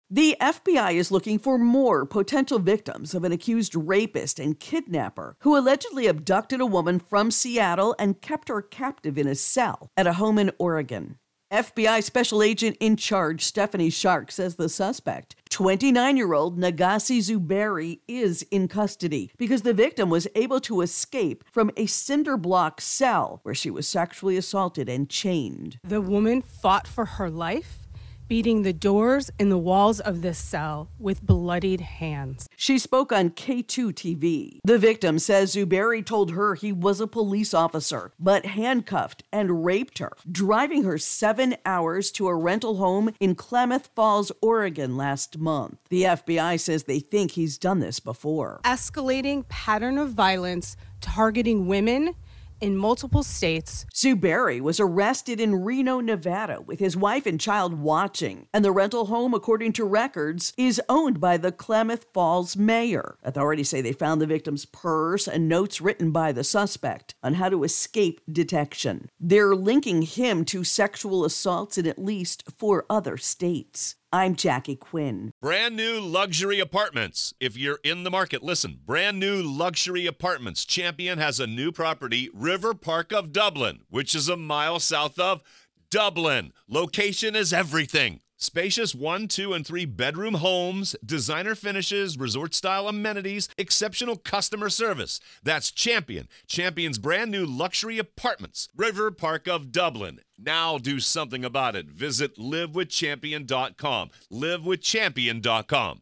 SOUND COURTESY: KATU-TV ((mandatory on-air credit)) ((KATU is pronounced KAY'-too))